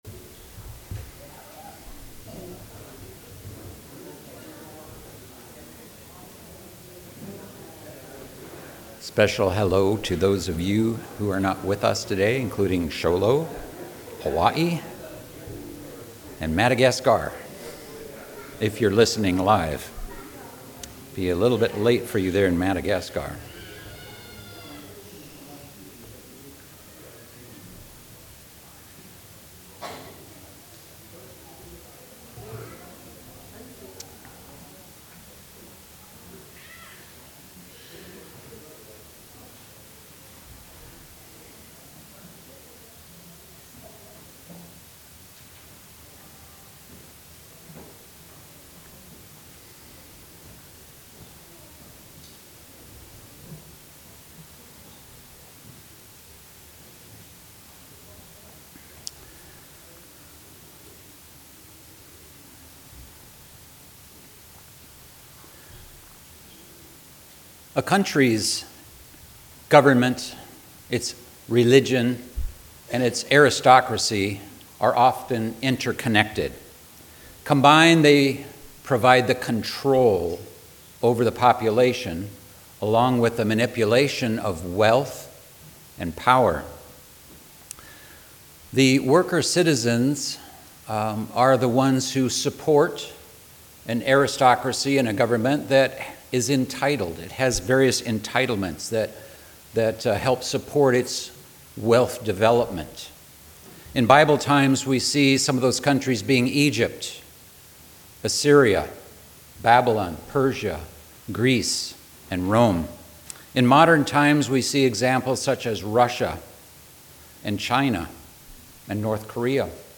Bible Study: Is God’s Church “Christian”?